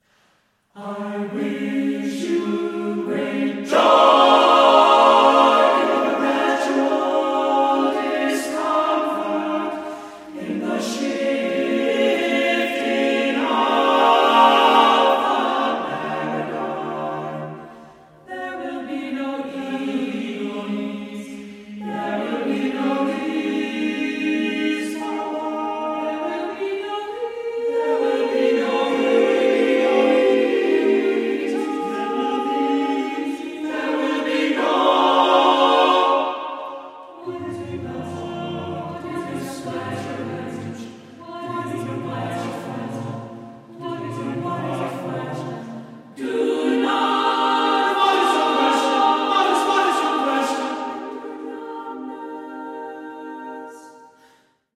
• Genres: Classical, Vocal
choral works
exuberant joy